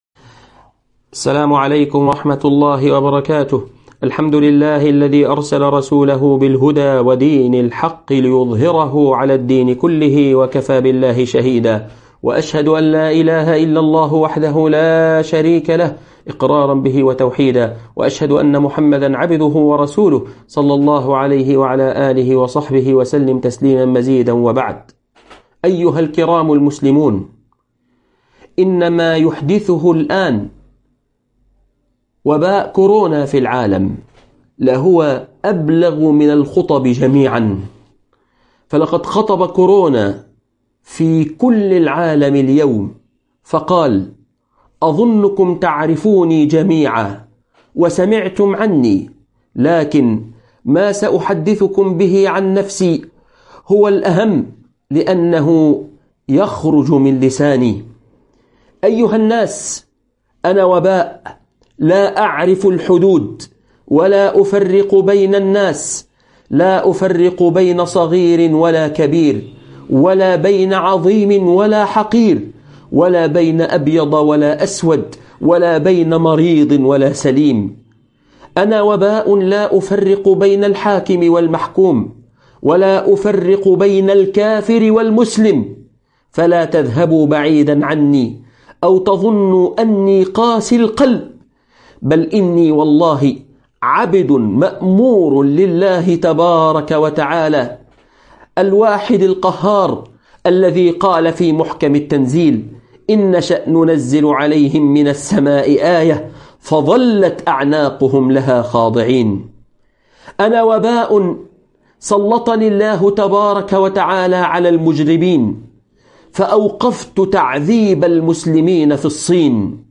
خطيب الجمعة | كورونا والناس